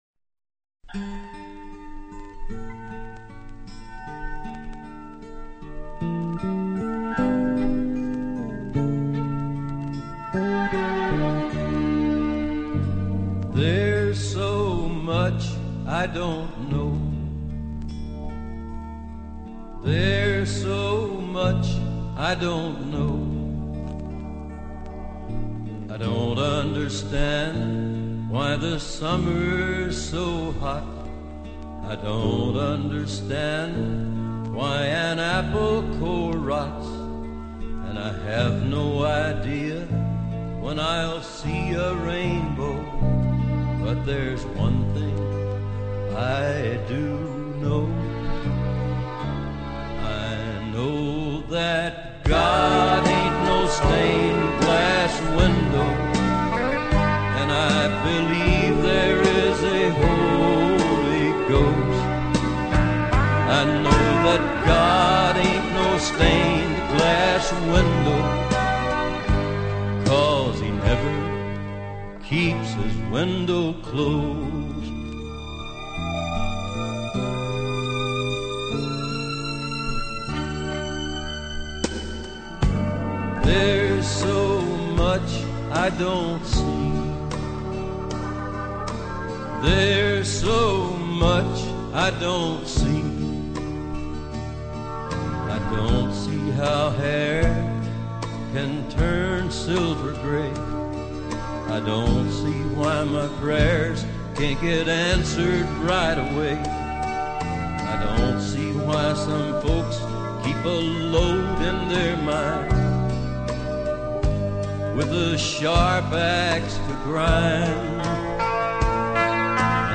Жанр: Folk, World, & Country